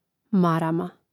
màrama marama